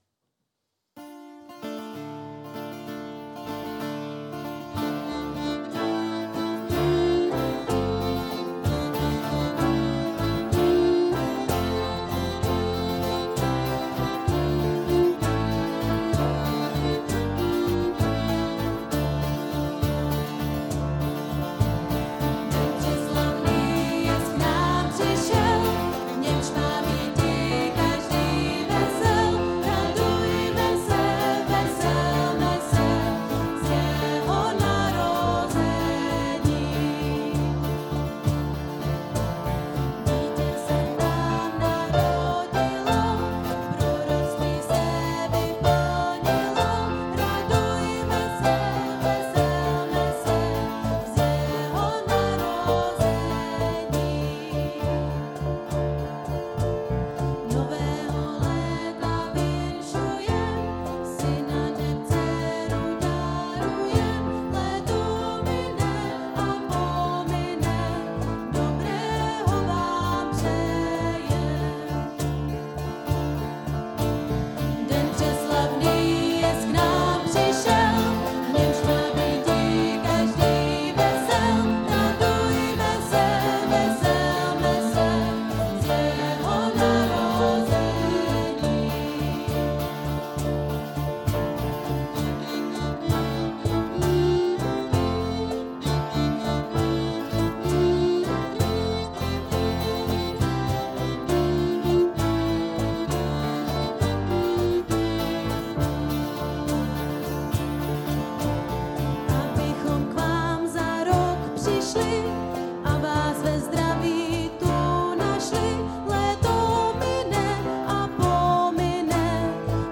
Půlnoční bohoslužba